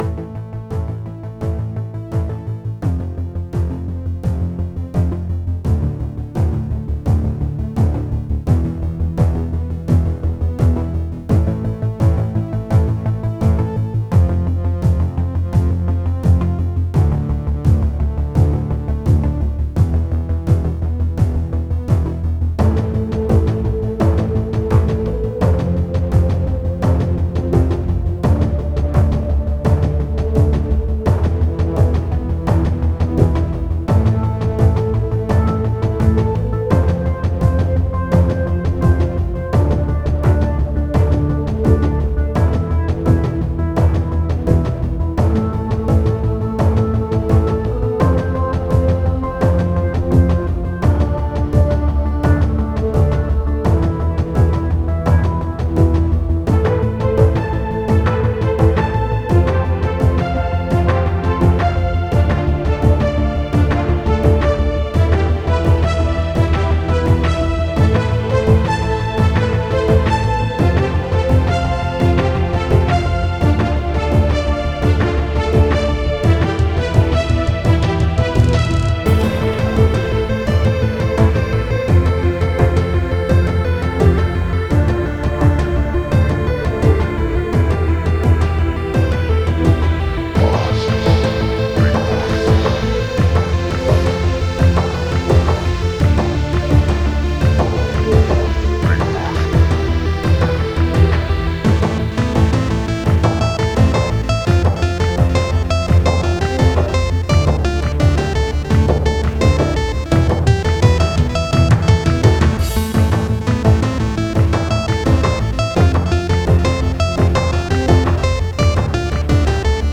This is chip combined with other stuff.